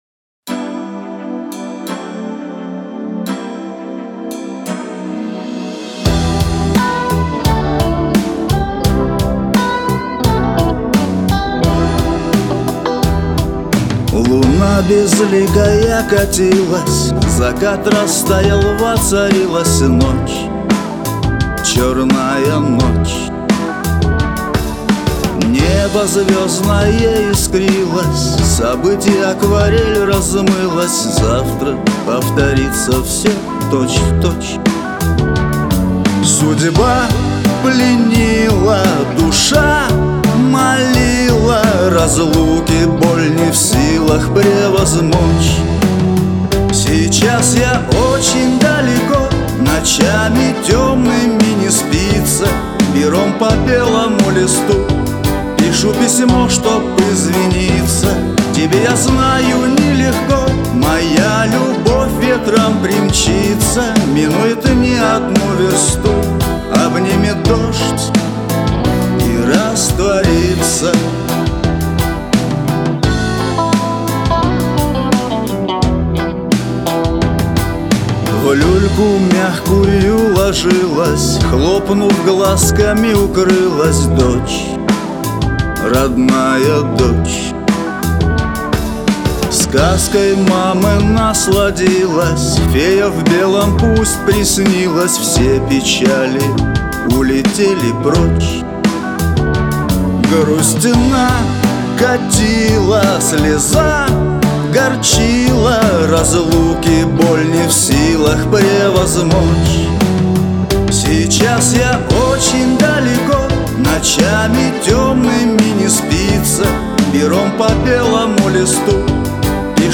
Главная » Файлы » Шансон 2016